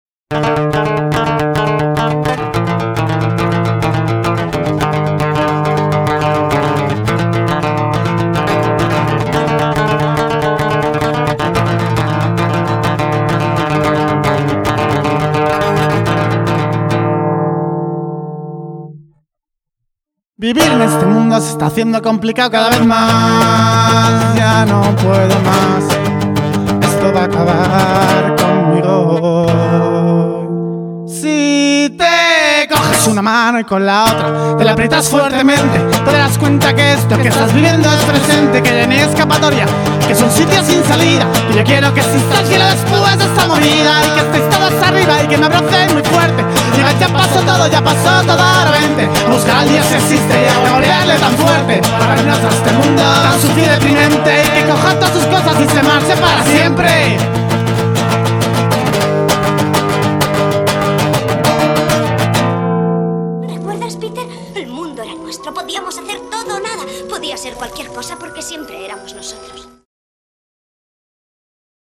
cantautores